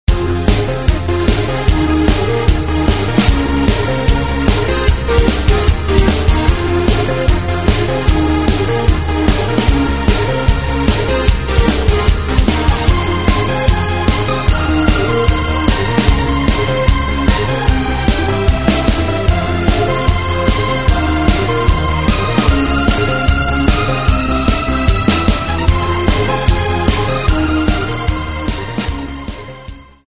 Video Game Music